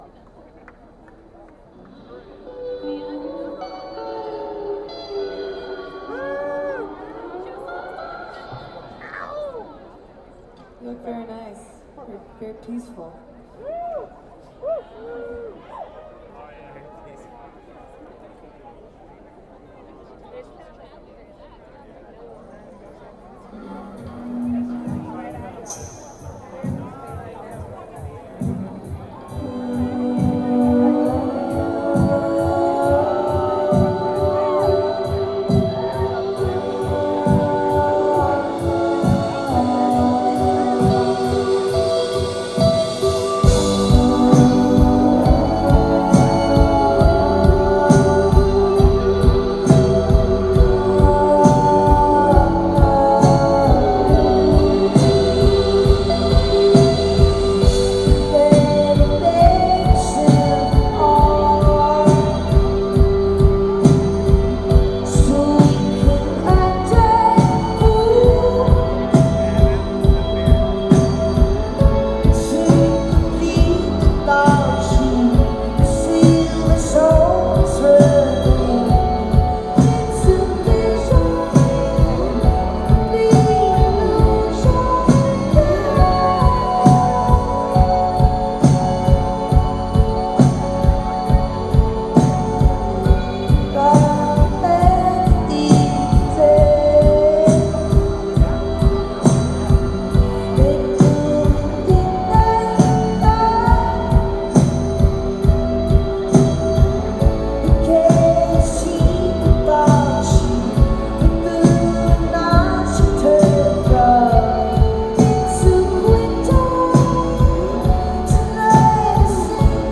Prospect Park 07-27-10